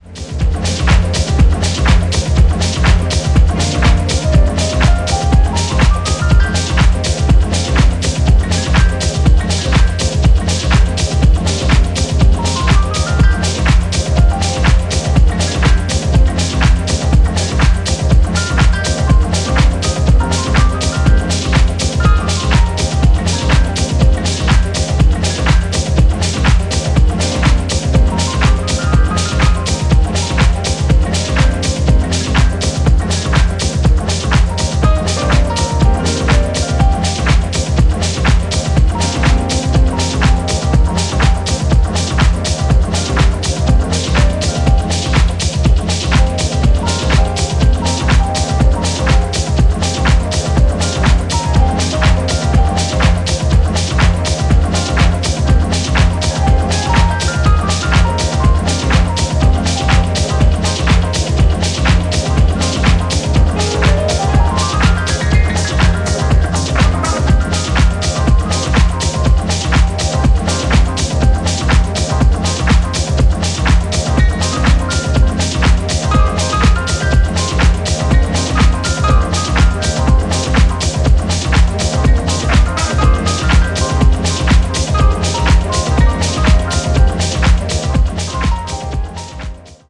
the dark, filtered chugger
an amazing piano solo